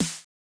ITA Snare.wav